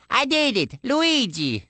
One of Luigi's voice clips from the Awards Ceremony in Mario Kart: Double Dash!!